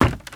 STEPS Wood, Creaky, Run 07.wav